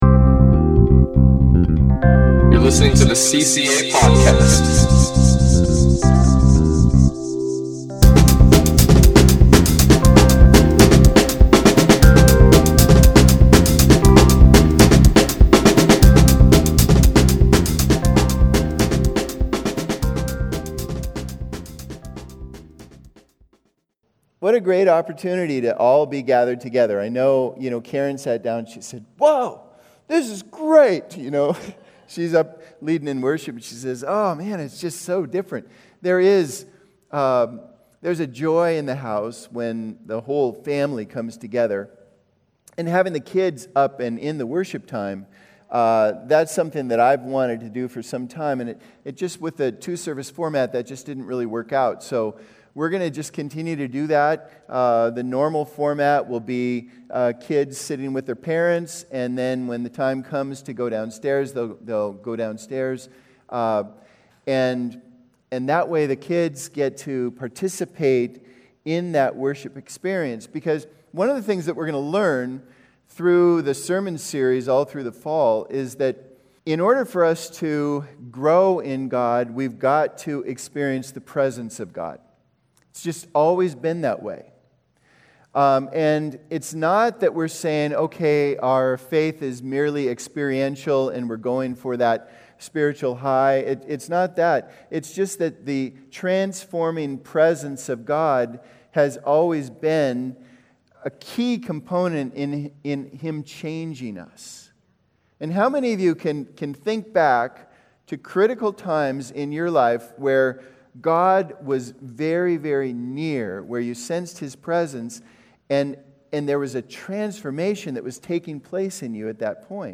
Message - Calvary Christian Assembly